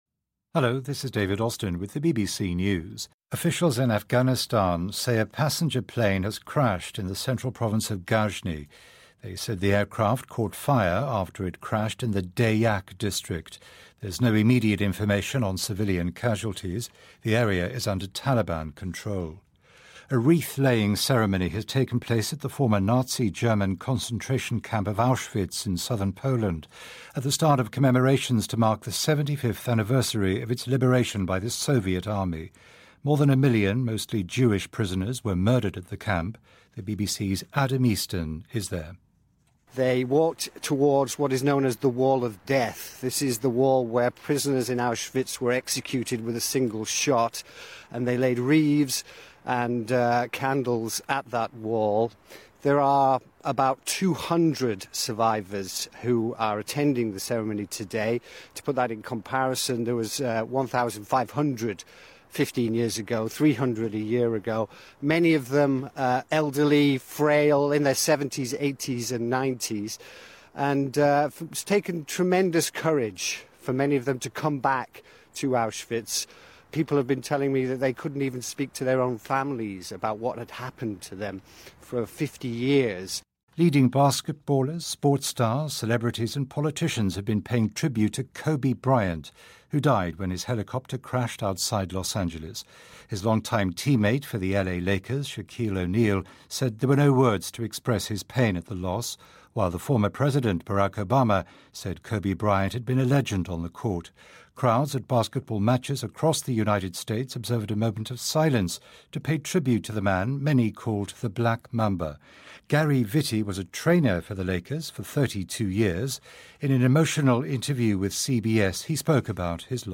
News
英音听力讲解:美国各界人士悼念篮球明星科比